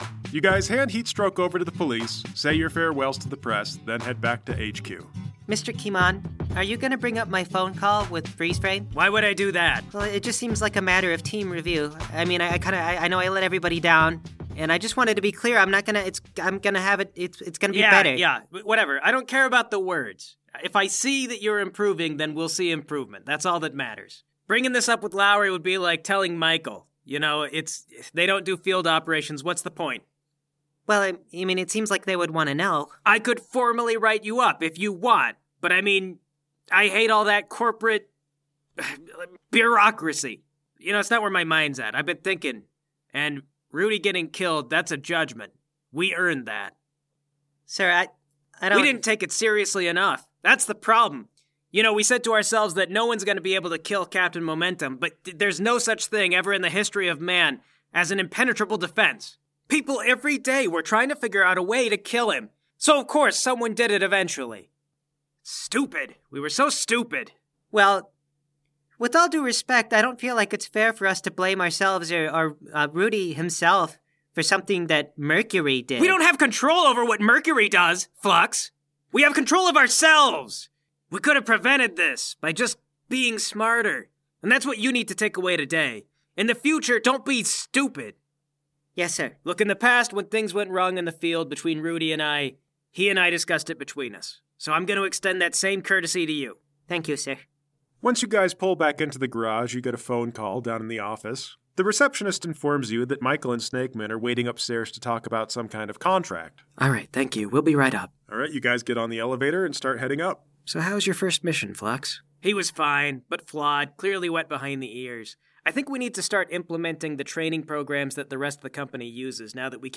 Comedy # Audio Drama